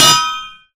anvil_land.ogg